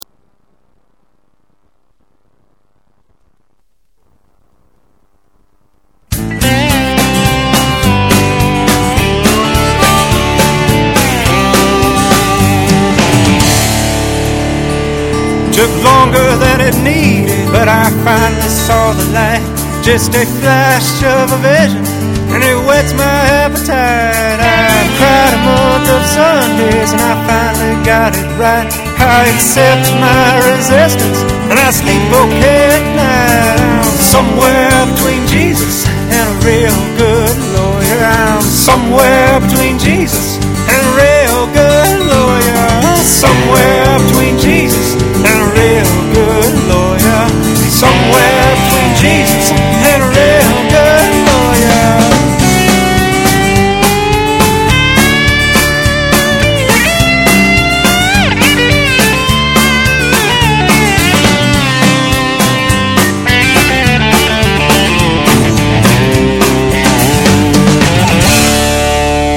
mixture of roots, folk, rock, country and blues.
lead guitar and adds beautifully-blended vocal